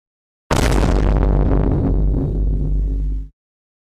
Vine Boom Sound Effect (DISTORTION) sound effects free download